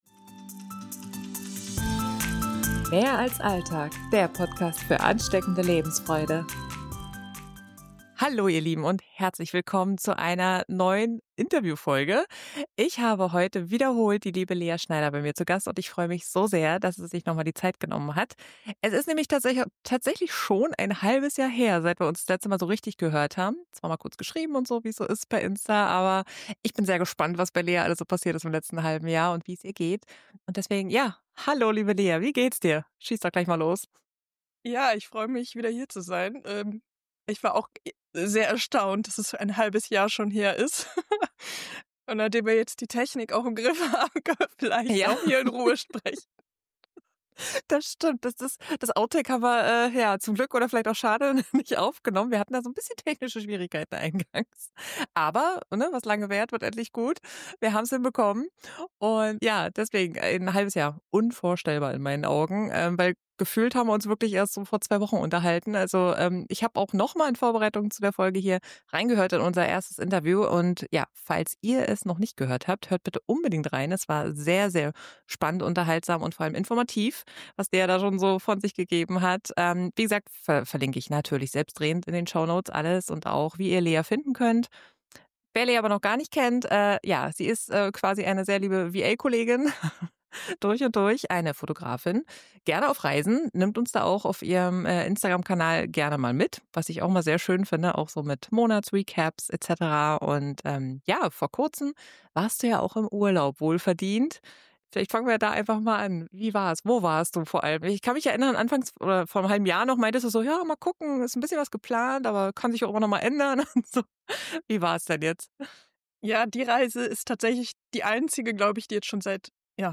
Heute erwartet euch ein inspirierendes und sehr unterhaltsames Interview-Gespräch über etliche Dinge, die das Leben noch bunter und lebenswerter machen!